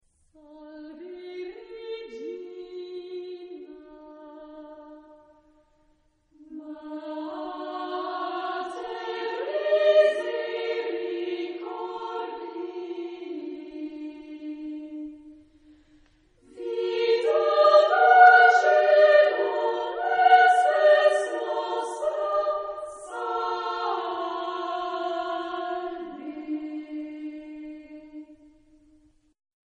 Genre-Style-Form: Sacred ; Prayer ; Hymn (sacred)
Type of Choir: SATB  (4 mixed voices )
Tonality: D dorian ; modal
Consultable under : 20ème Sacré Acappella